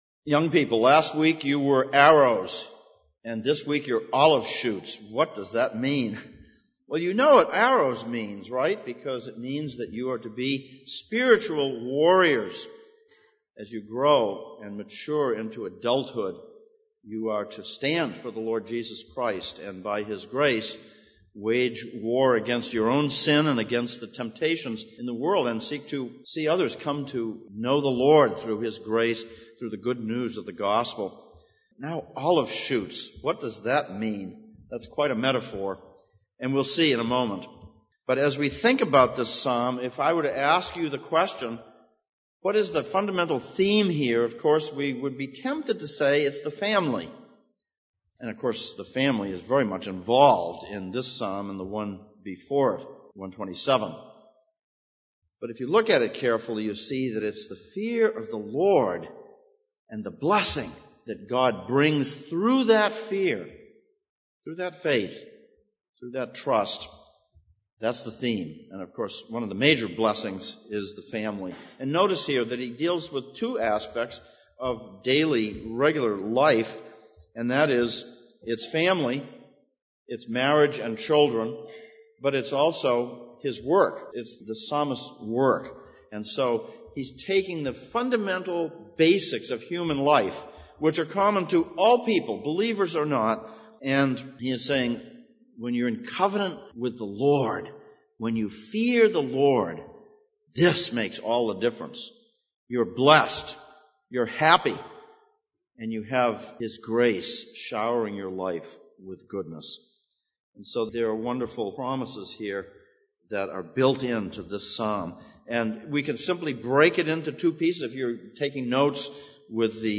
Psalms of Ascents Passage: Psalm 128:1-6, Colossians 3:18-25 Service Type: Sunday Morning « 7.